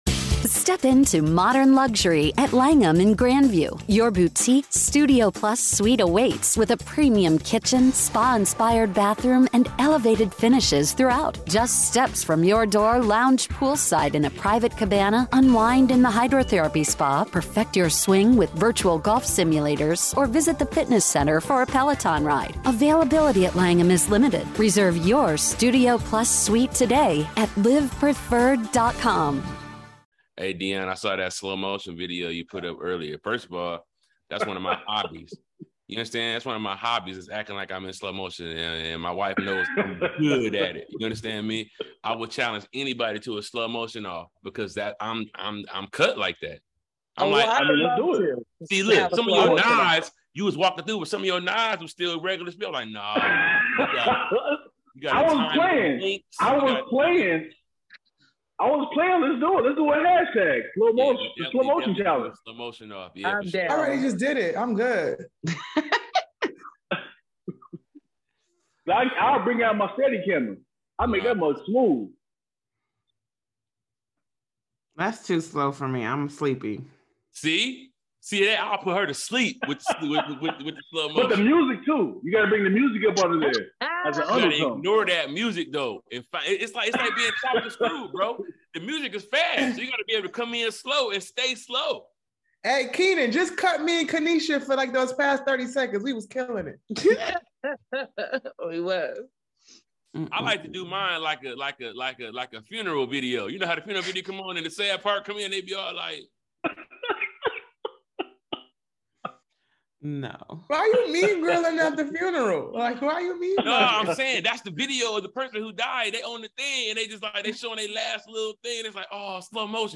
Each week, the SquADD will debate topics and vote at the end to see what wins.